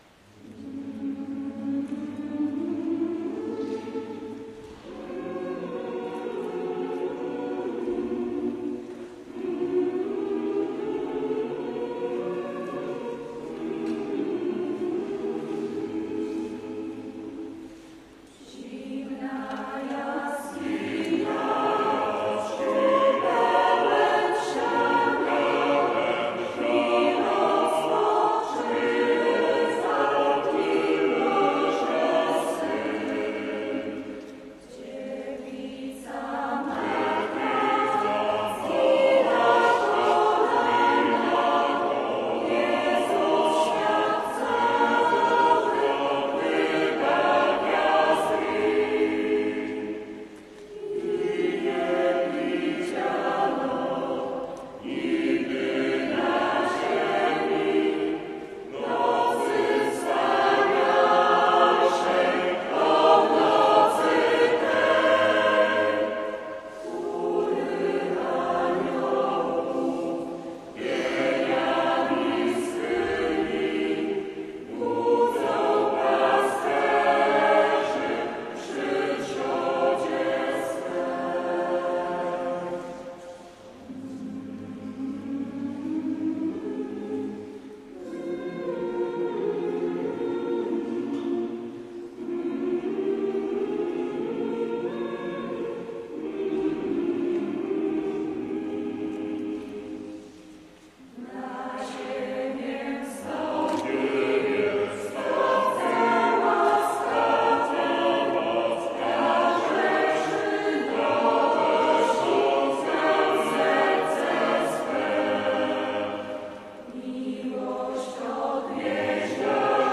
Program występu w I Lubelskim Festiwalu Chórów Parafialnych: